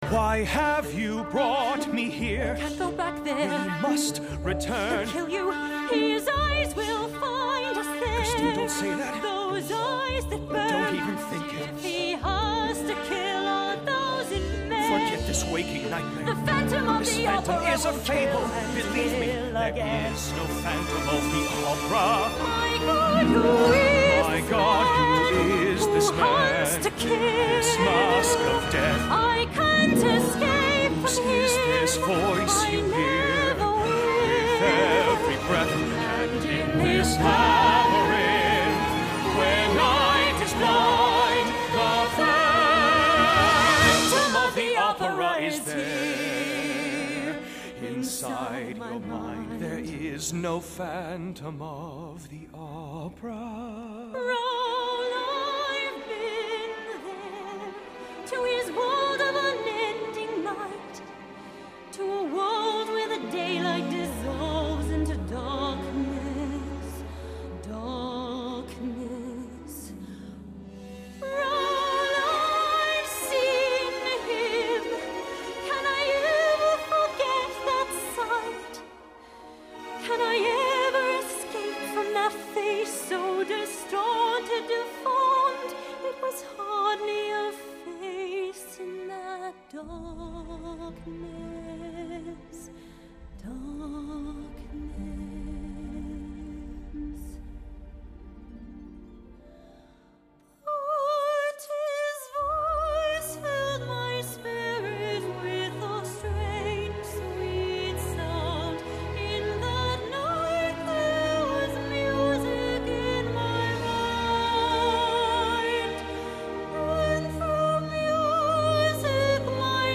音乐类型：电影配乐
集合全好莱坞及百老汇最能歌善舞演员 亲自演唱